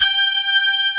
Index of /90_sSampleCDs/AKAI S-Series CD-ROM Sound Library VOL-1/BRASS SECT#1